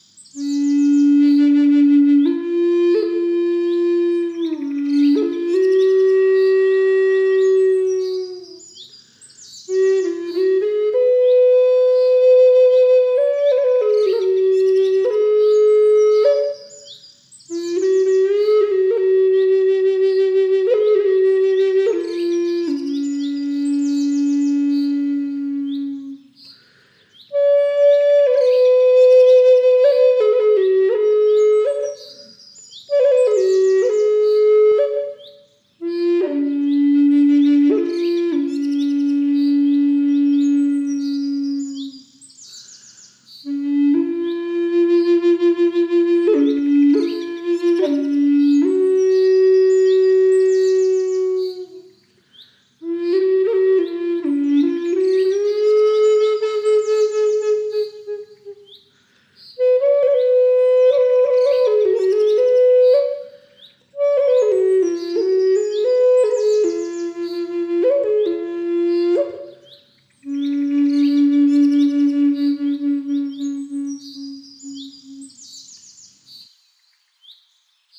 Lakota flute in key of D bass (Re bass) – Avaye Lotus
Walnut wood, approximately 70 cm long, with a stable coating and resistant to environmental factors, along with a bag, birth certificate, one-year warranty and free shipping.
Lakota-key-re-BASS.mp3